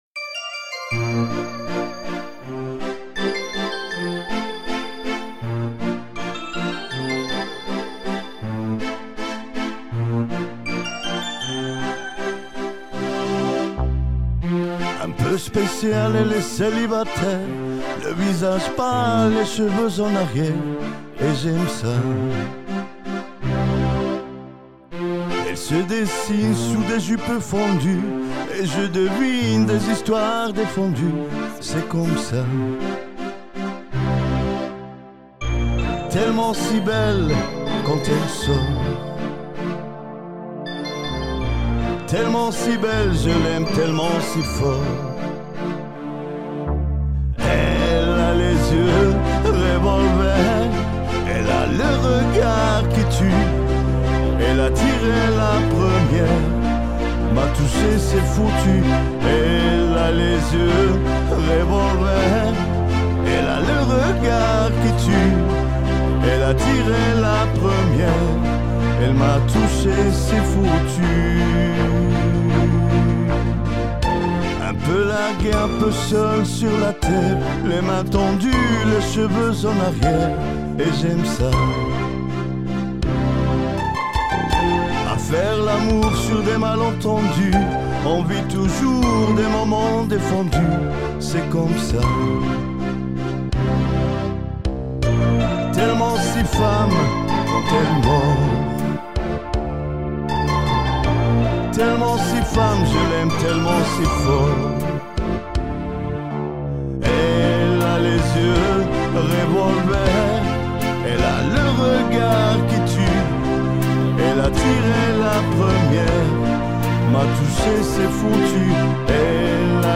Une reprise de la chanson enregistrée et montée
Karaoké